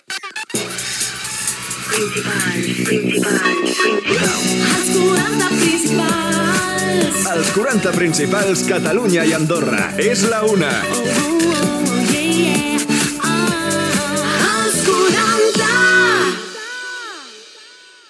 Indicatiu horari de la ràdio "Catalunya i Andorra"
FM